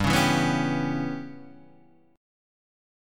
G6b5 chord